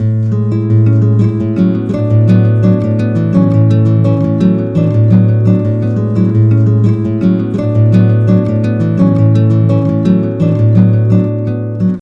The simplest delay function will create a copy of the input, add some silence to the beginning of the copy, and combine it with the original input.
offset = 700